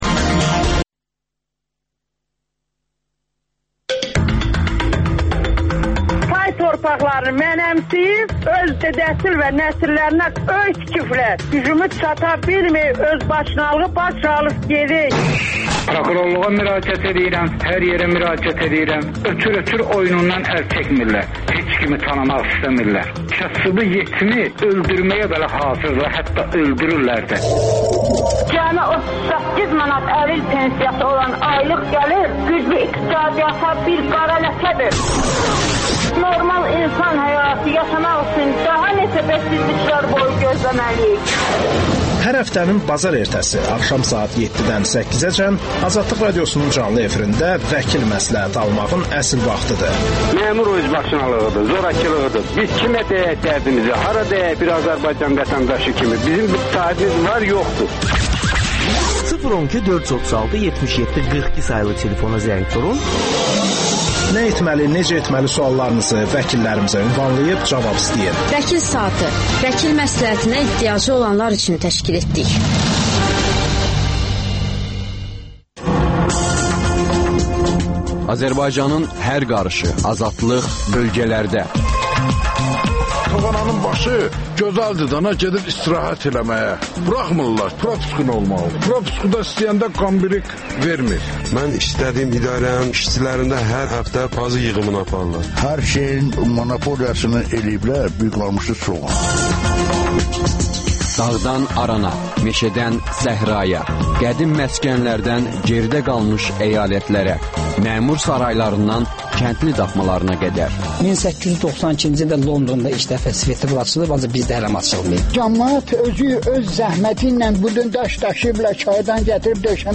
AzadlıqRadiosunun müxbirləri ölkə və dünyadakı bu və başqa olaylardan canlı efirdə söz açırlar. Günün sualı: ABŞ prezidentinin bəyanatlarından sonra nələr dəyişir?